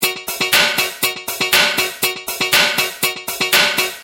Funk-loop-120-bpm.mp3